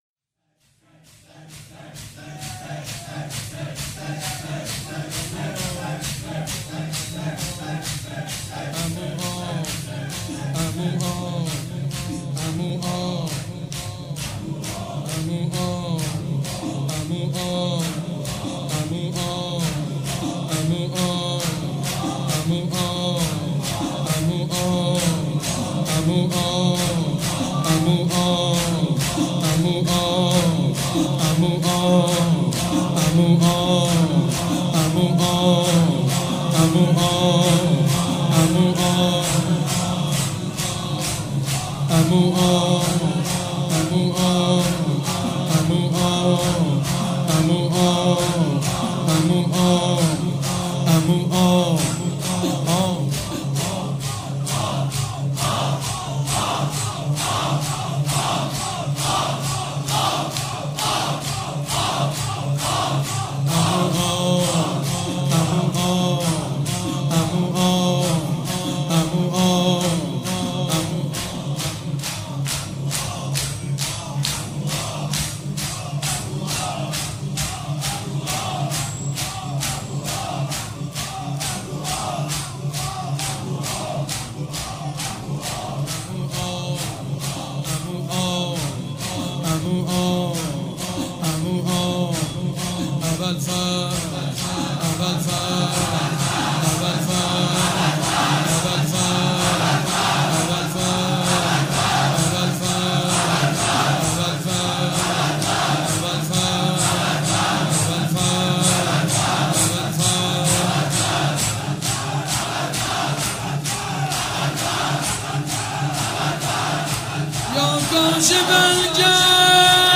شب نهم محرم 95_شور_عمو آب...